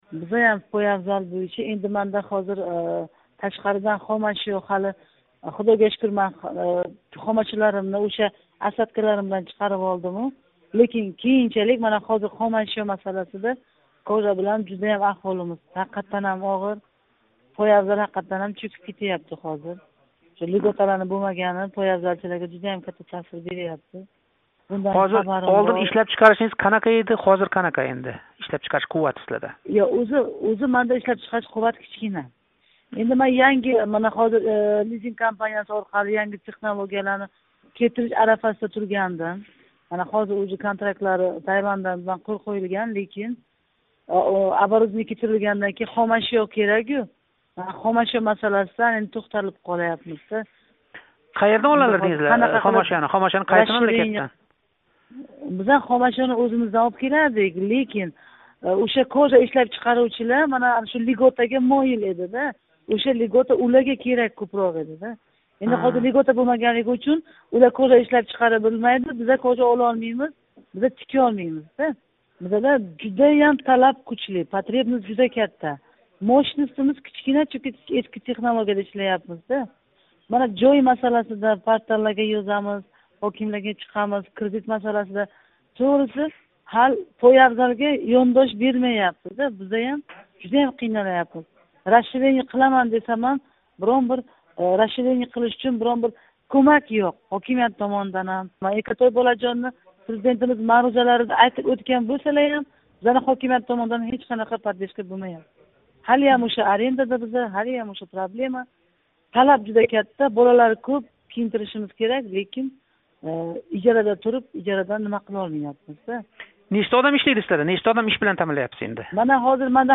суҳбат.